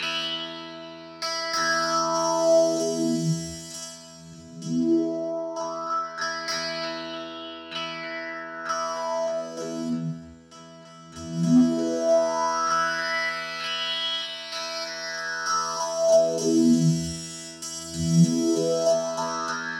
ElectricBerimbau2_97_E.wav